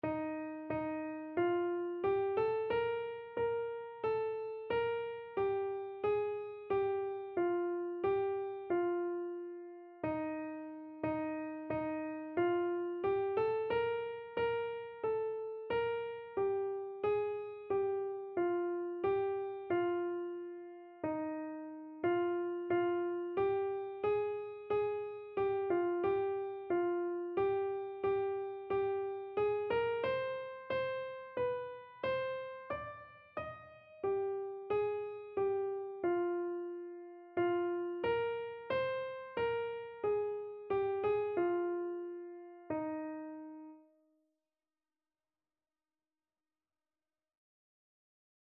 Christian Christian Keyboard Sheet Music Break Forth, O Beauteous Heavenly Light
Free Sheet music for Keyboard (Melody and Chords)
4/4 (View more 4/4 Music)
Eb major (Sounding Pitch) (View more Eb major Music for Keyboard )
Keyboard  (View more Intermediate Keyboard Music)
Traditional (View more Traditional Keyboard Music)